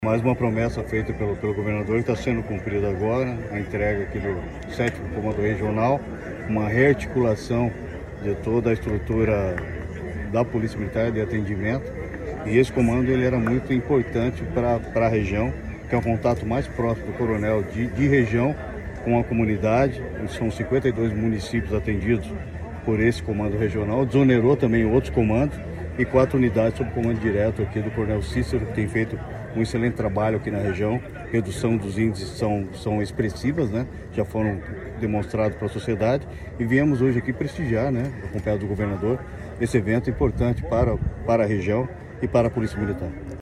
Sonora do secretário da Segurança Pública, Hudson Teixeira, sobre a sede do Comando Regional da PMPR em Pato Branco